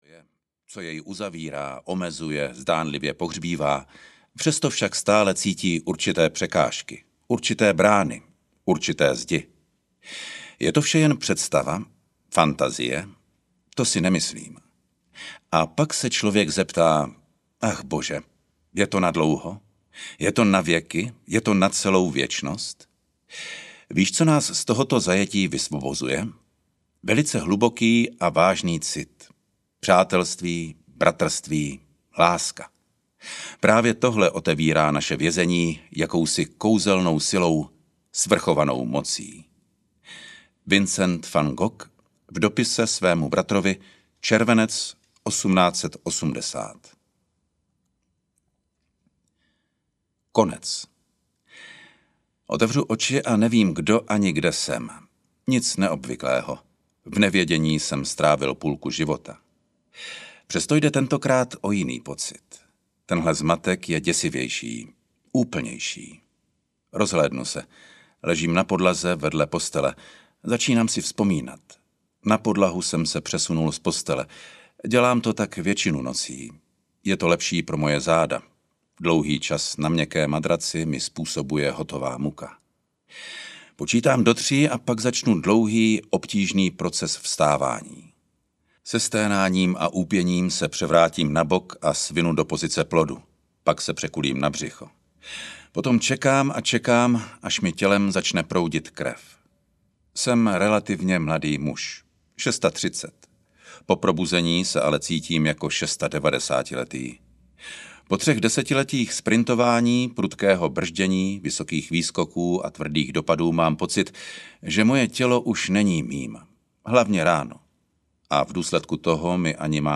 OPEN audiokniha
Ukázka z knihy